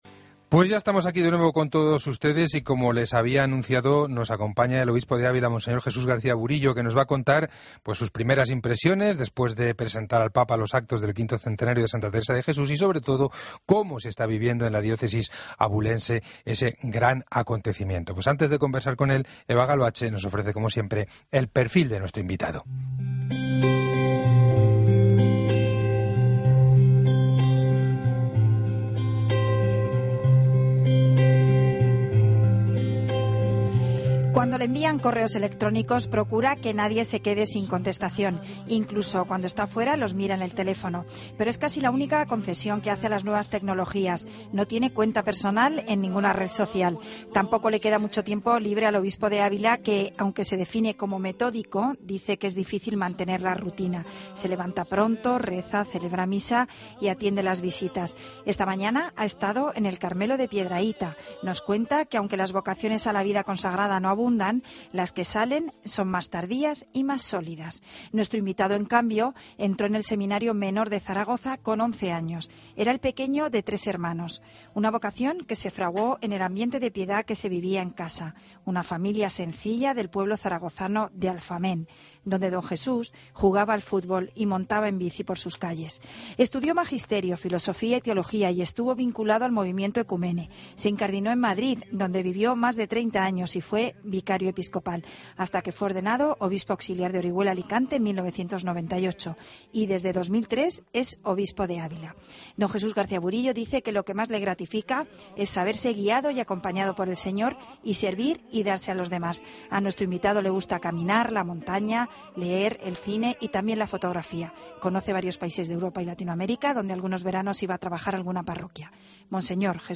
Escucha la entrevista completa a monseñor Jesús García Burillo en 'El Espejo' de COPE